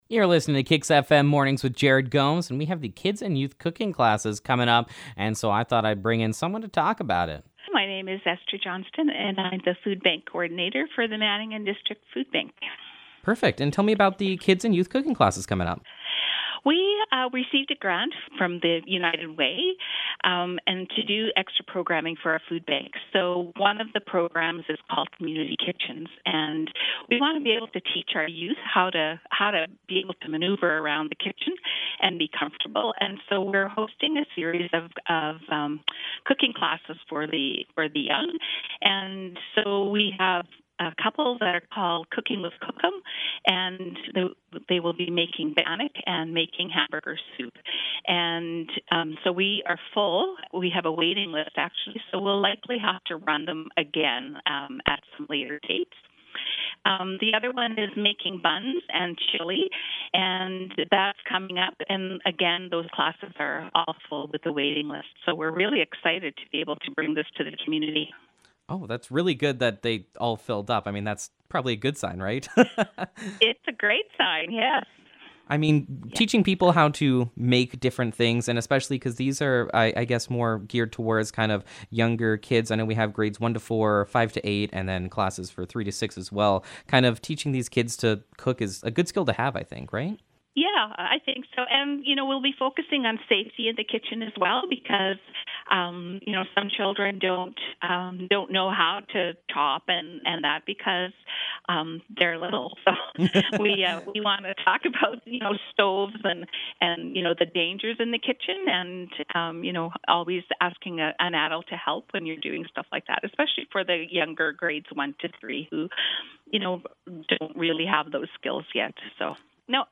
Kids & Youth Cooking Classes Interview
Kids-can-cook-Interview-FINAL.mp3